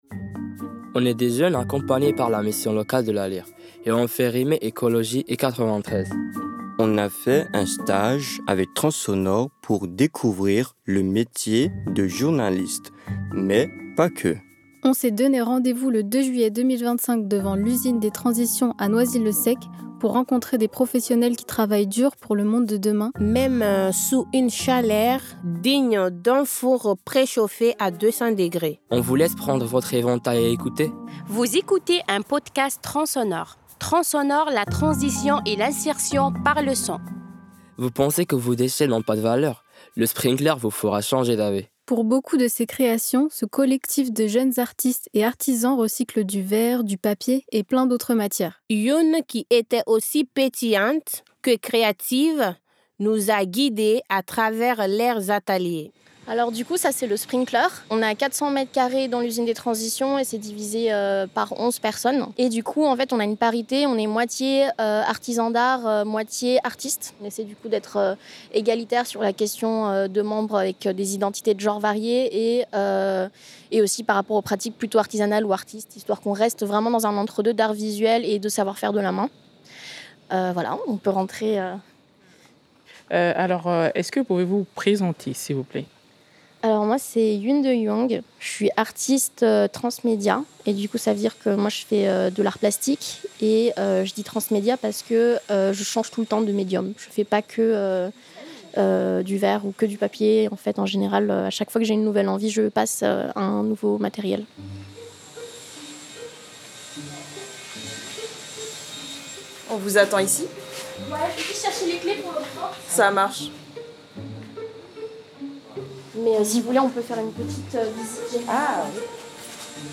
On s’est donné·e rendez-vous le 2 juillet 2025 devant l’Usine des Transitions à Noisy-le-sec, pour rencontrer des professionnel·les qui travaillent dur pour le monde de demain, même sous une chaleur digne d’un four préchauffé à 200°.